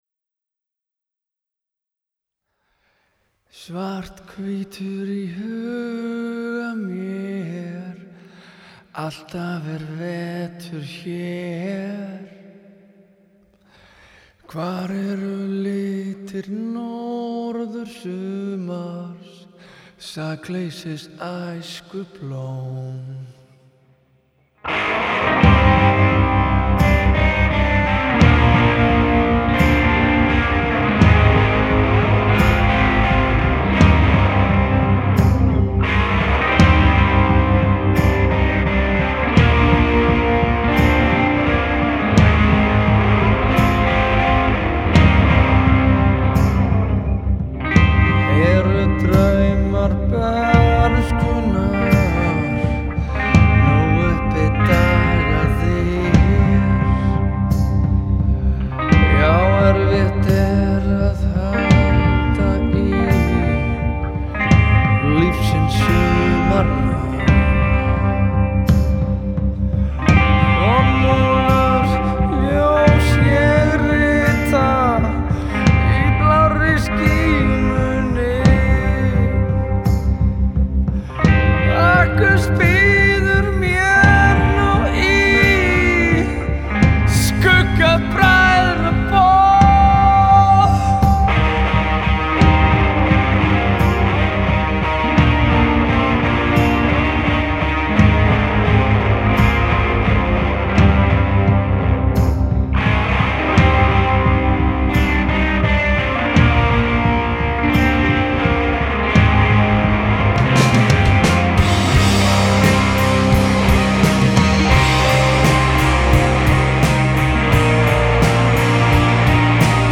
Жанр: Metal.